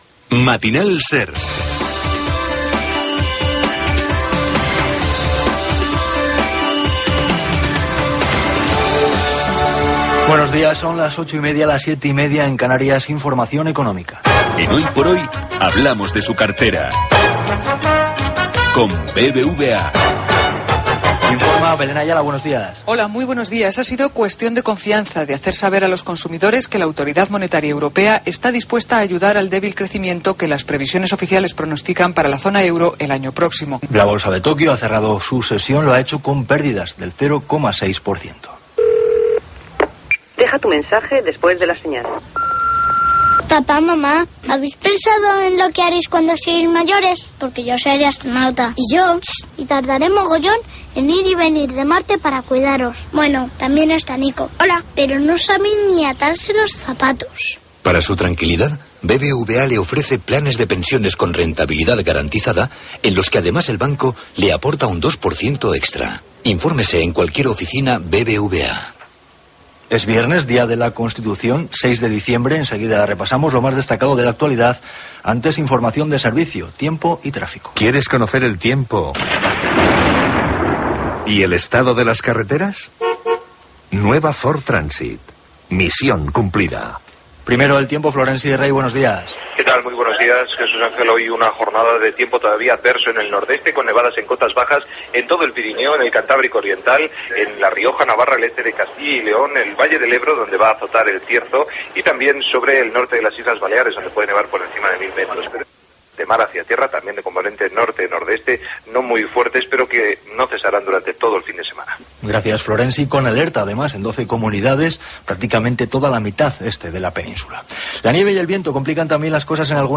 Hora, informació econòmica, publicitat, data, el temps, el trànsit, publicitat, número d'ahir del sorteig de l'ONCE.
Info-entreteniment